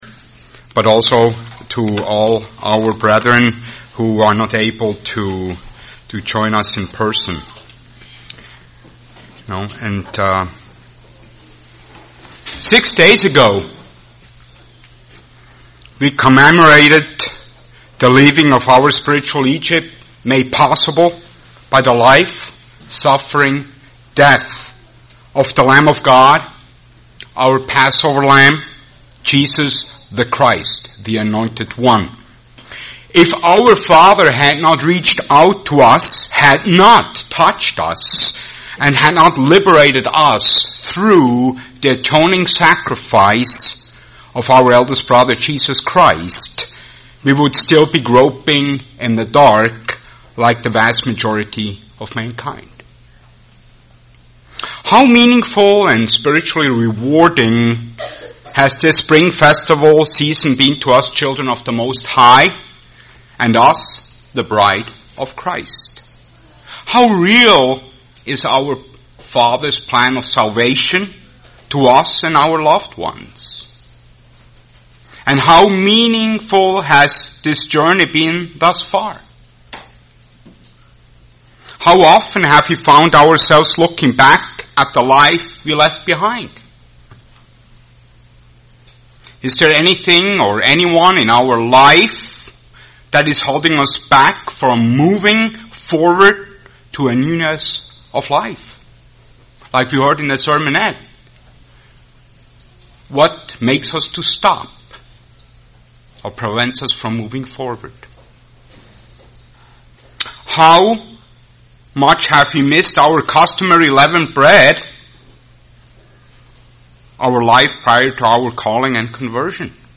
Given in Twin Cities, MN
UCG Sermon Days of Unleavened Bread Studying the bible?